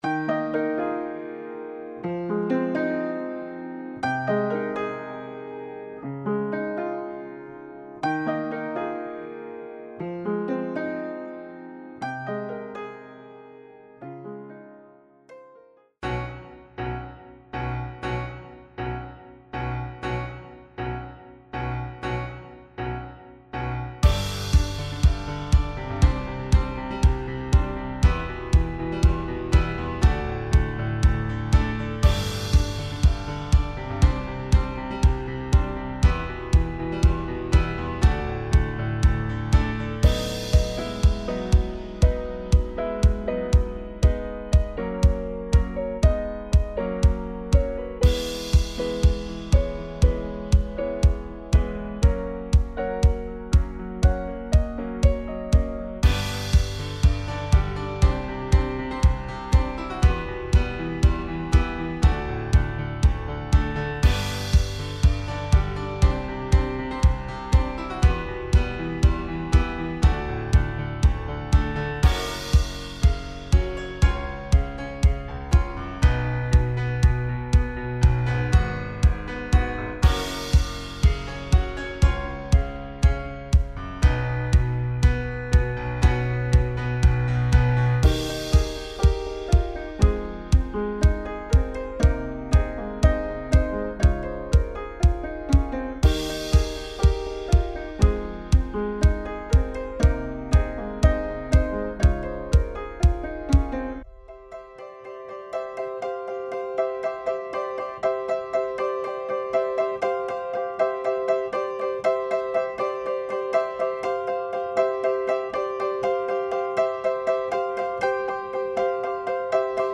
ポップロング明るい穏やか
BGM